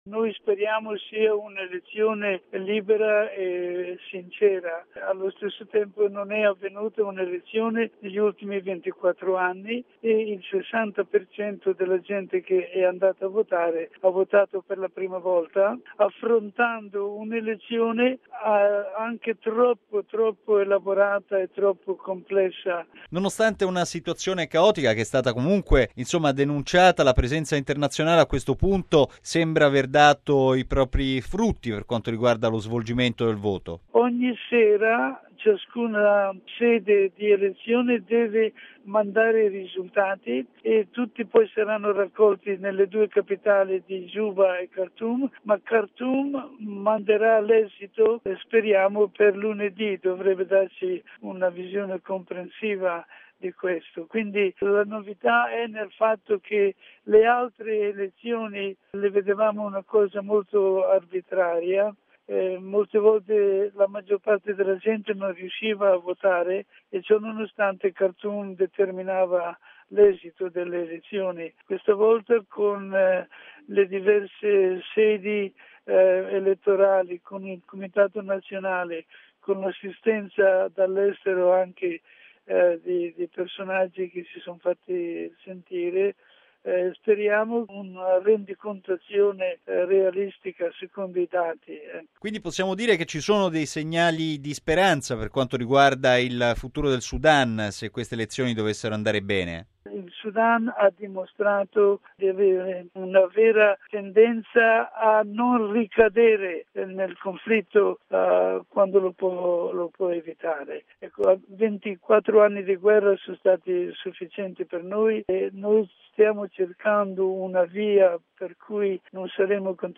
Segnali di speranza dalle elezioni in Sudan: la testimonianza del vescovo di Rumbek, Cesare Mazzolari